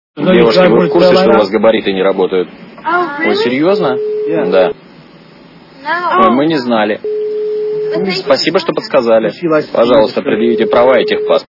» Звуки » Из фильмов и телепередач » Плохой Лейтенант (гоблин) - Девушки у вас габариты не работают. да а мы не знали